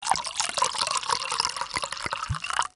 pourMilk2.wav